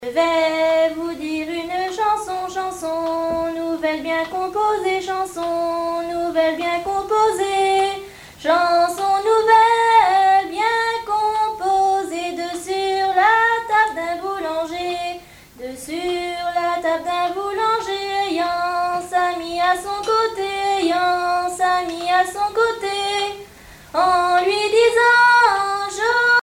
Genre dialogue
Chansons traditionnelles